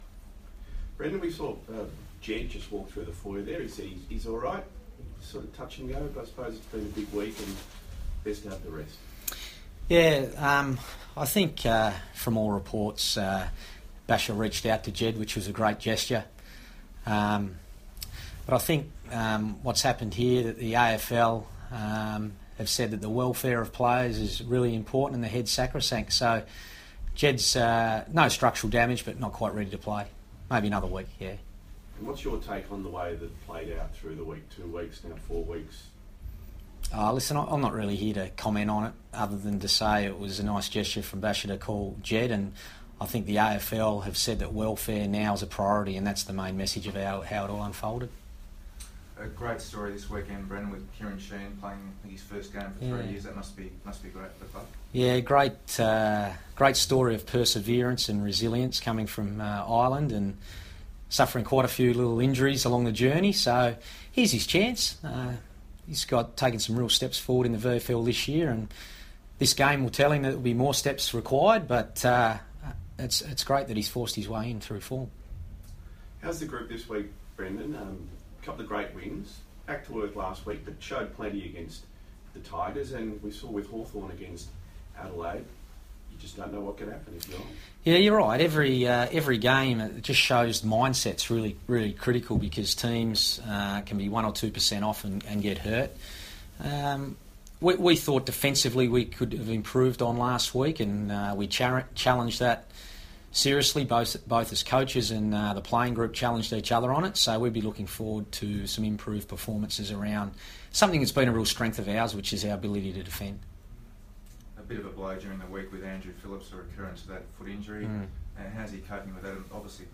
Brendon Bolton press conference - June 30
Carlton coach Brendon Bolton fronts the media ahead of the Blues' Round 15 clash against Adelaide.